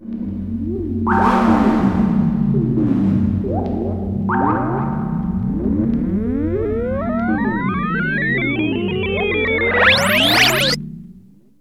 AMBIENT ATMOSPHERES-4 0002.wav